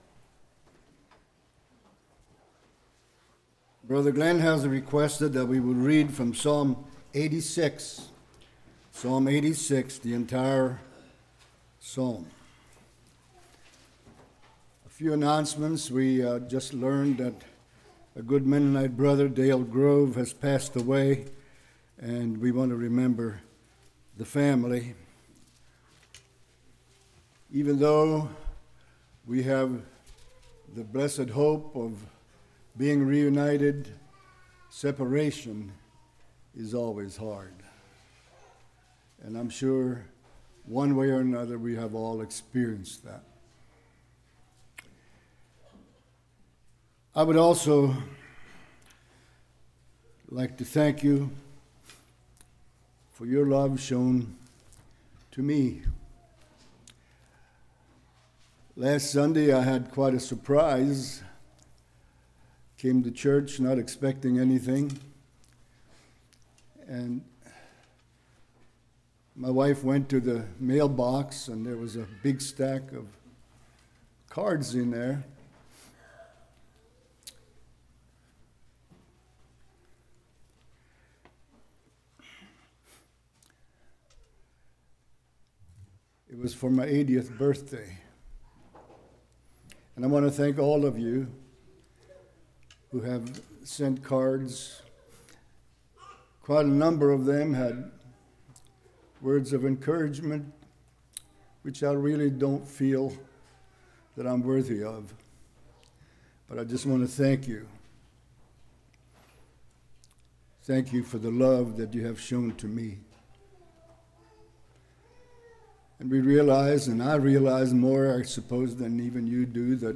Passage: Psalms 86 Service Type: Morning